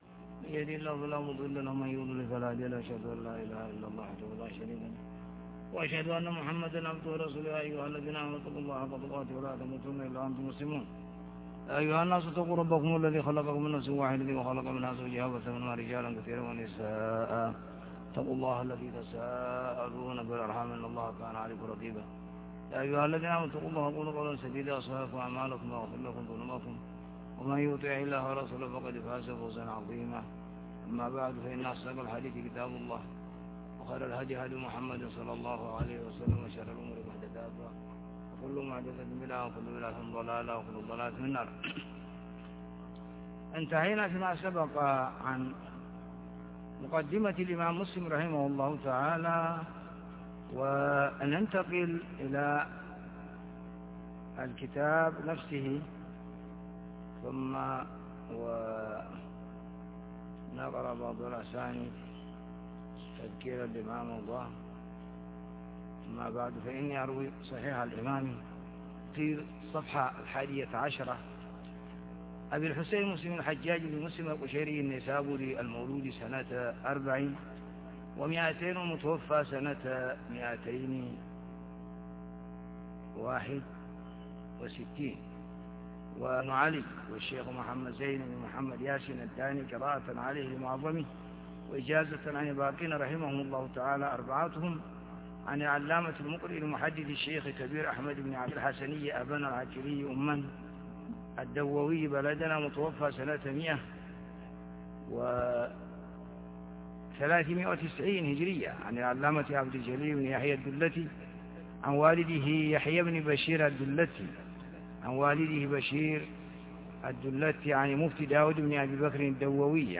الدرس 001 -بَابُ معرفة الْإِيمَانِ، وَالْإِسْلَامِ، والقَدَرِ وَعَلَامَةِ السَّاعَةِ- كتاب الإيمان - ح 1